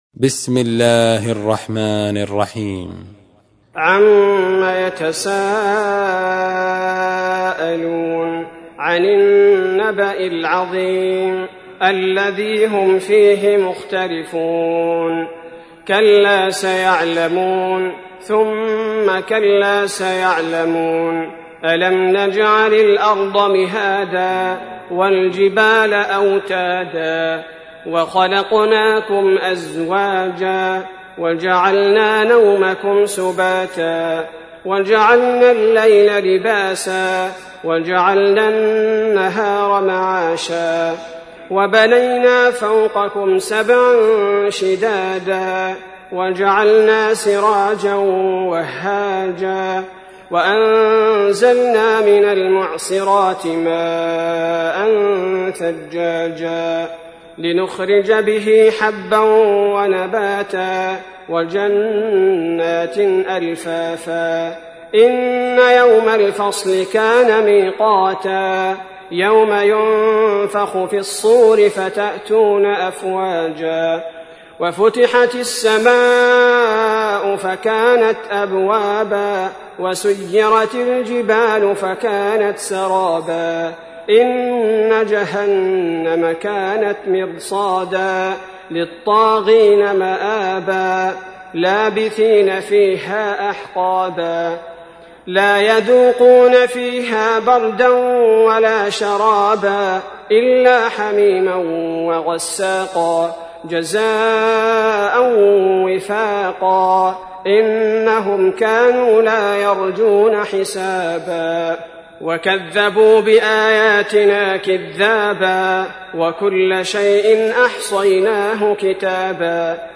تحميل : 78. سورة النبأ / القارئ عبد البارئ الثبيتي / القرآن الكريم / موقع يا حسين